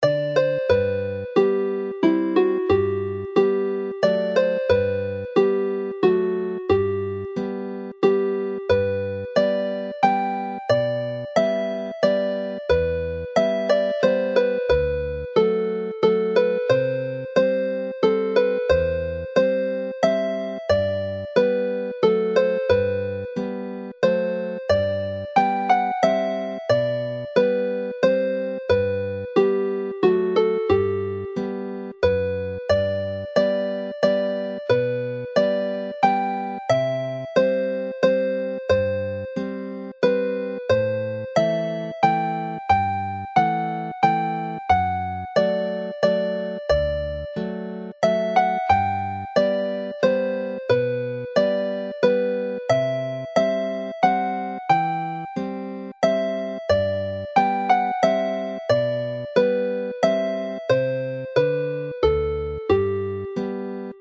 These three waltzes, all in G major
Play slowly